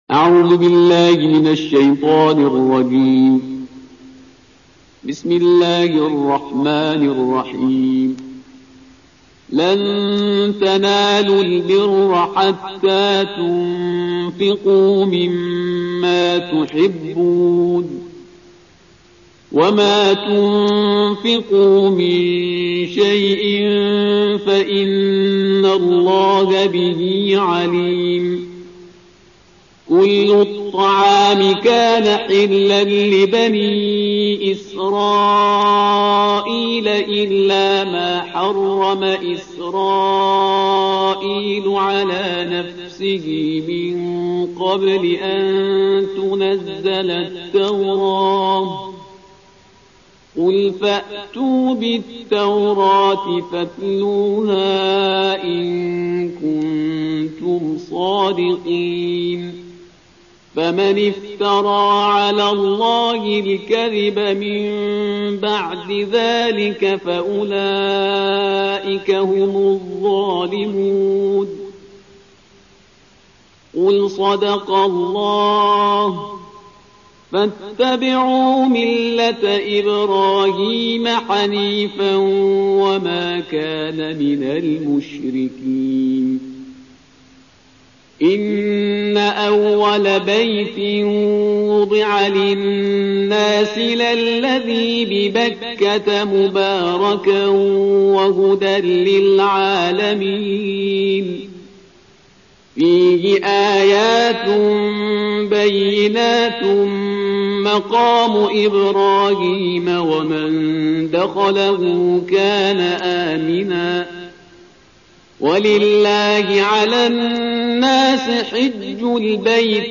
قرائت جزء و دعای روز چهارم و مطالب خواندنی - تسنیم